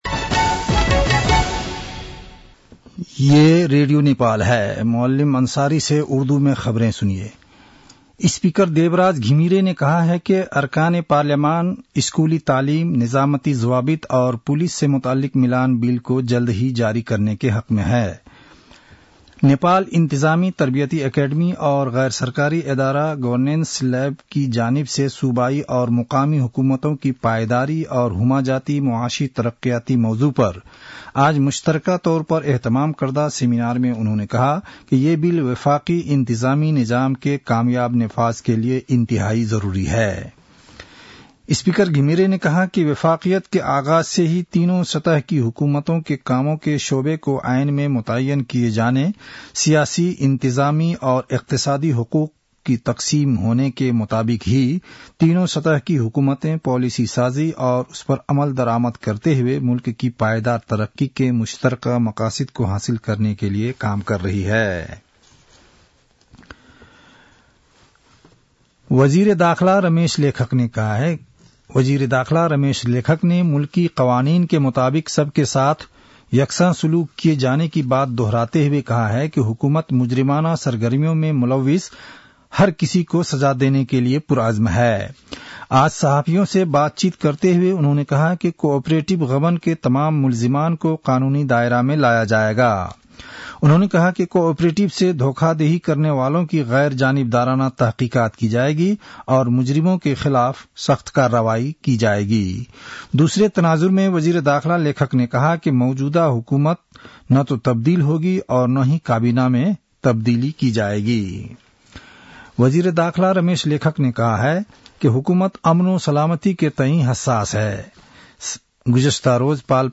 उर्दु भाषामा समाचार : ५ पुष , २०८१
Urdu-news-9-04.mp3